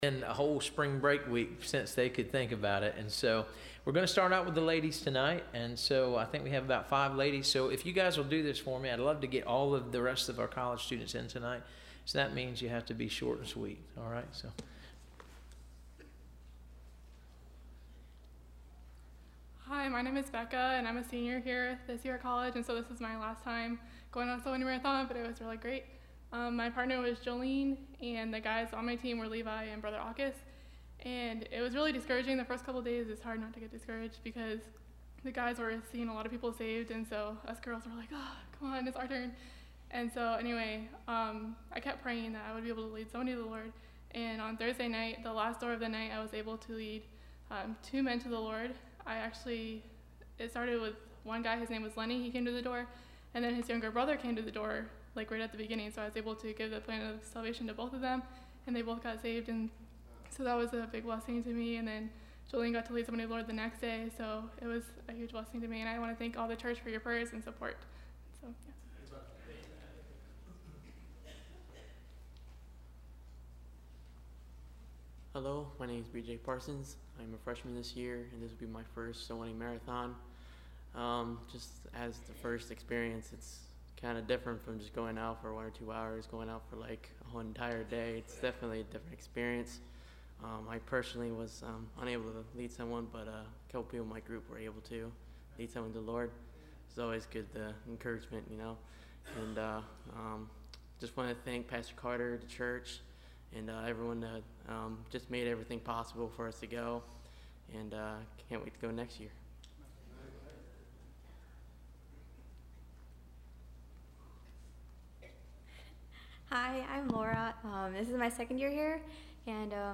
Service Type: Wednesday College